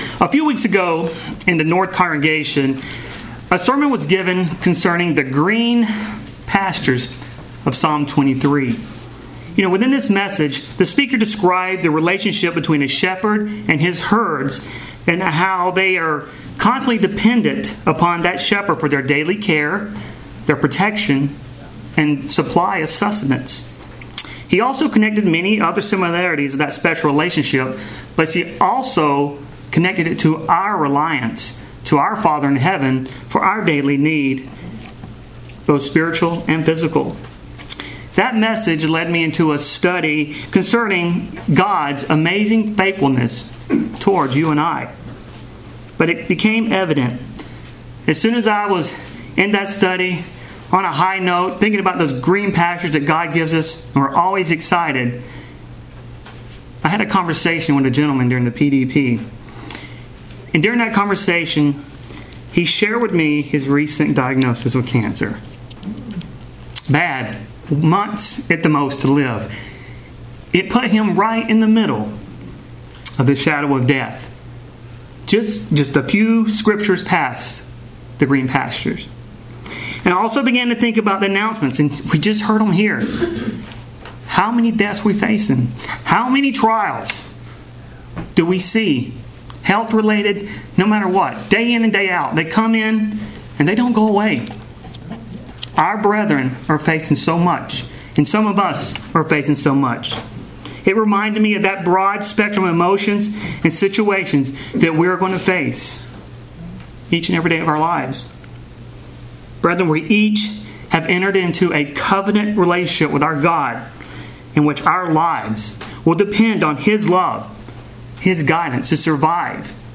Sermons
Given in Cincinnati North, OH